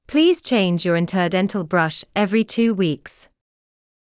ﾌﾟﾘｰｽﾞ ﾁｪﾝｼﾞ ﾕｱ ｲﾝﾀｰﾃﾞﾝﾀﾙ ﾌﾞﾗｯｼ ｴﾌﾞﾘｰ ﾄｩ ｳｨｰｸｽ